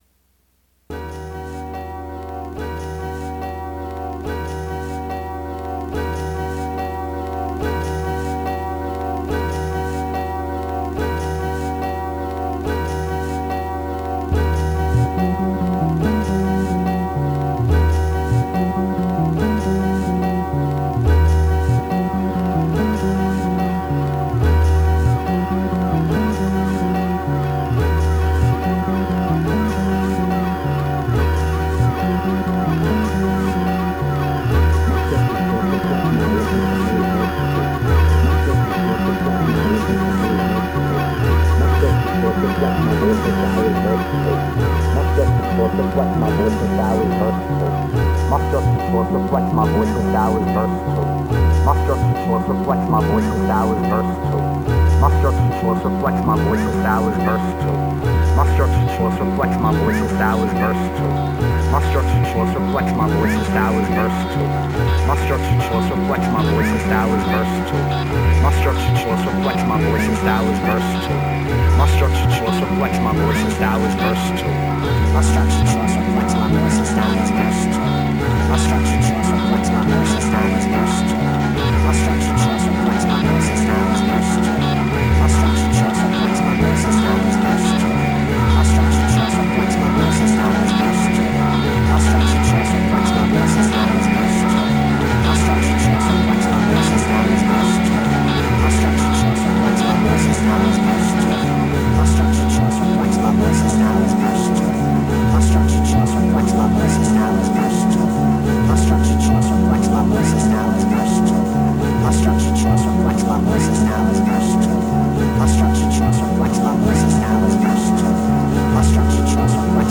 I had an unsynchronized jam with both 202s free looping through mercury x. One is being used more for volume swells with overtones of closely looped vocal notes and various noisy samples. The other is layering short melodic and rhythmic loops that give it something of a structure.
I like to let the layers get a bit washed out and then reign it back in so an analogy of walking through various busy places and eventually reaching an ocean where the sound dissolves into the background is kinda perfect here.